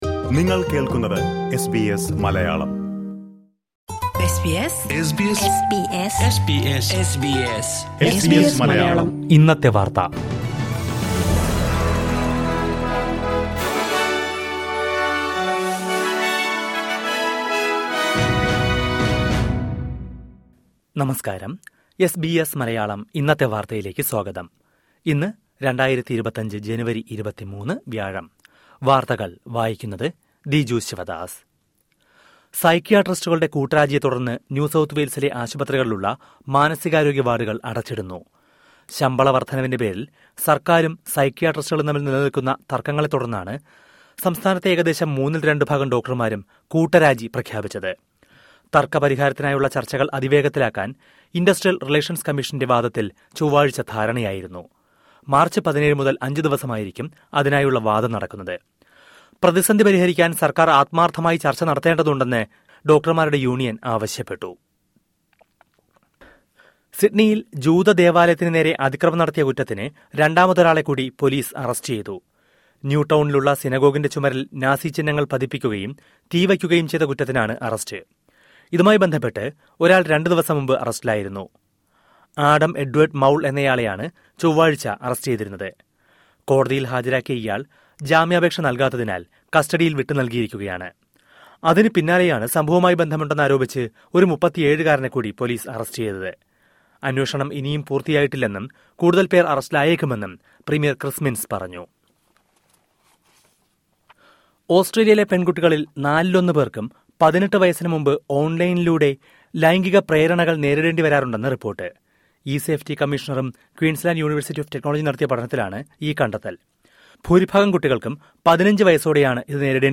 2025 ജനുവരി 23ലെ ഓസ്‌ട്രേലിയയിലെ ഏറ്റവും പ്രധാന വാര്‍ത്തകള്‍ കേള്‍ക്കാം...